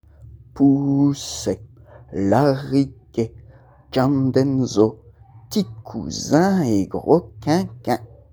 des airs et chansons traditionnels collectés en Wallonie, dans leur version brute enregistrée sur le terrain.